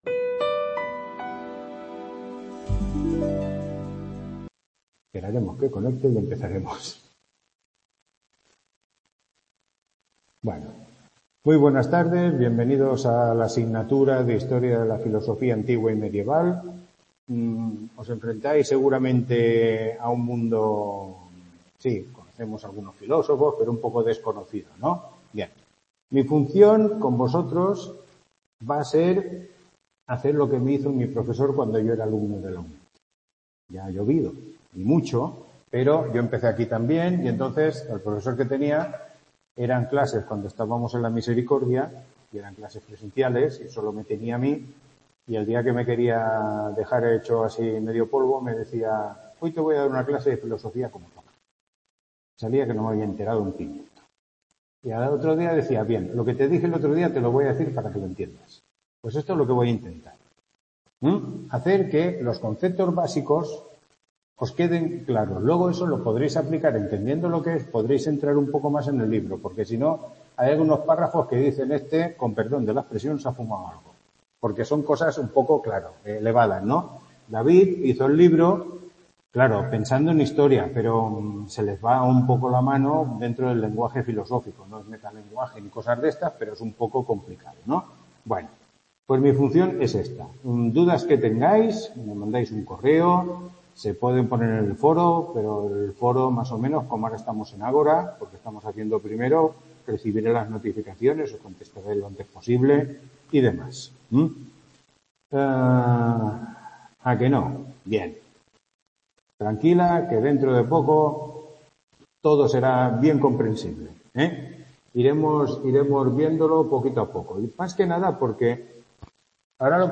Tutoría 1